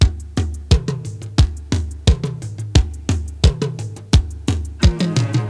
• 87 Bpm Breakbeat D Key.wav
Free drum loop sample - kick tuned to the D note. Loudest frequency: 811Hz
87-bpm-breakbeat-d-key-Ul7.wav